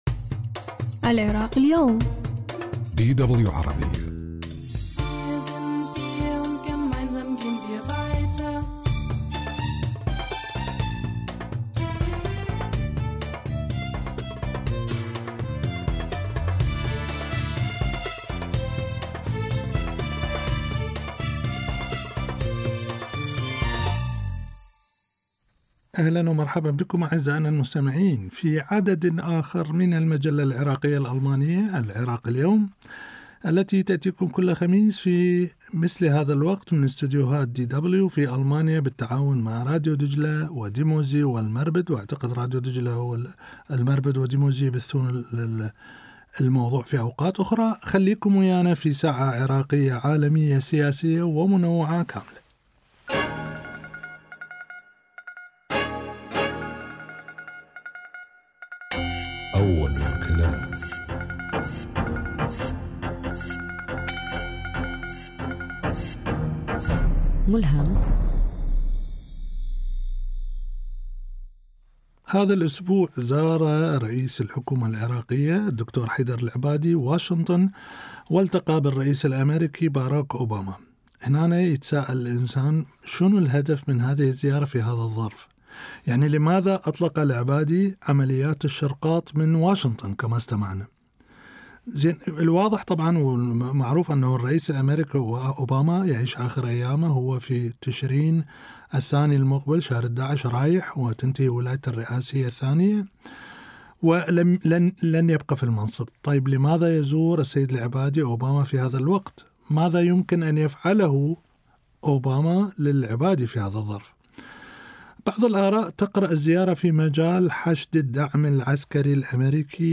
ضيوف الحوار